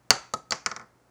Shells